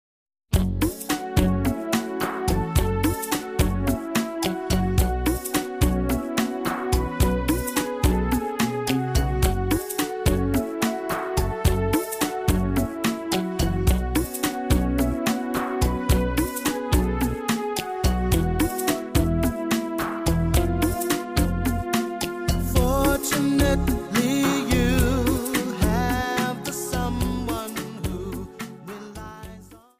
Rumba